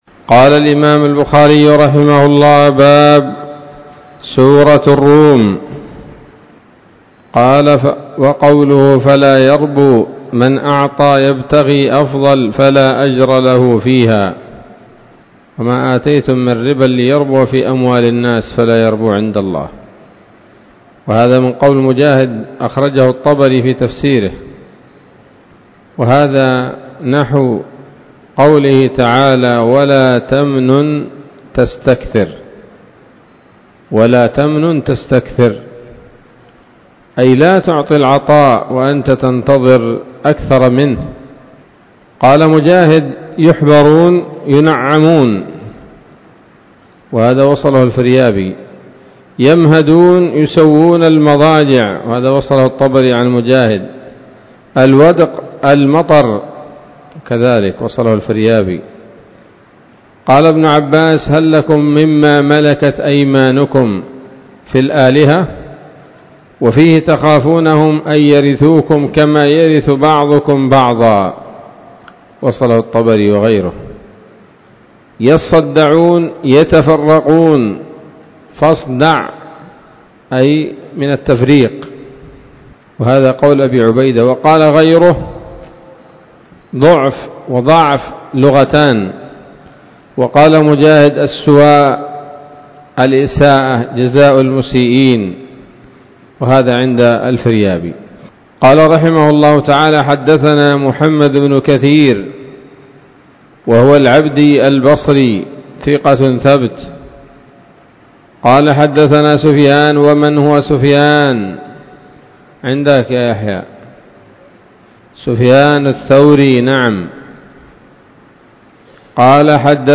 الدرس السابع والتسعون بعد المائة من كتاب التفسير من صحيح الإمام البخاري